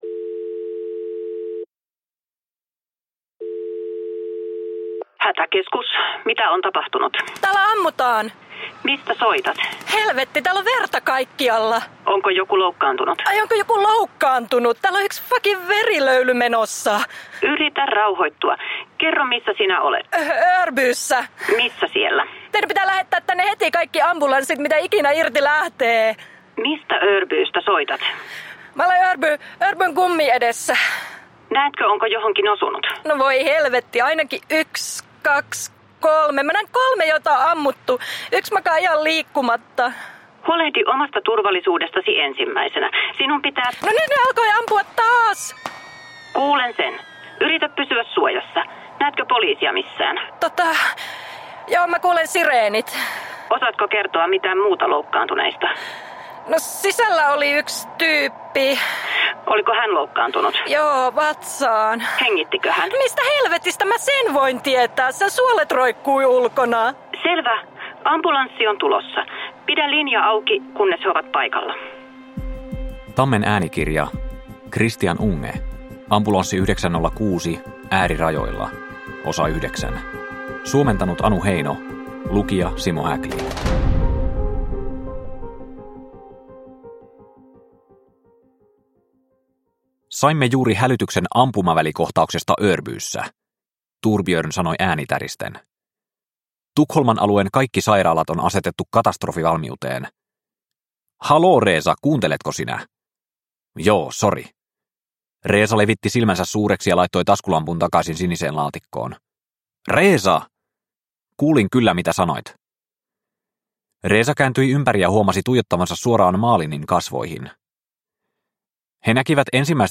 Ambulanssi 906 Osa 9 – Ljudbok – Laddas ner